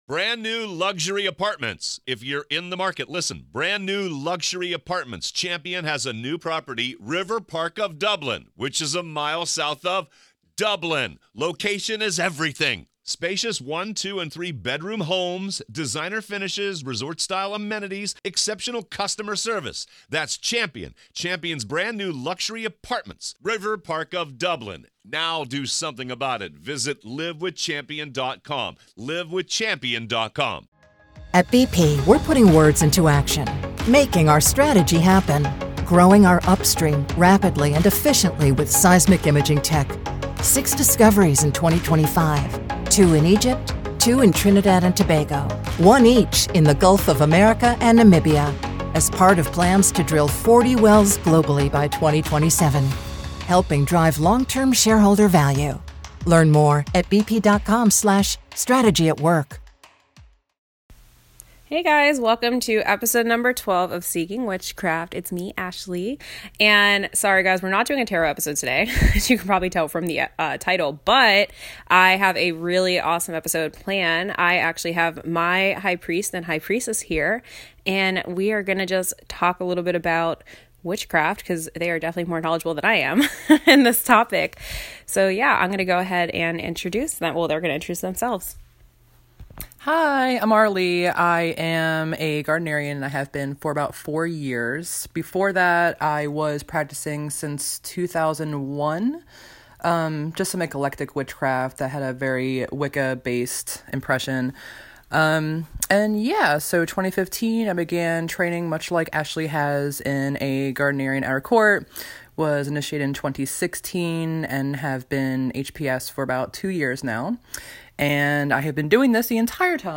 Interview with a Gardnerian High Priest and High Priestess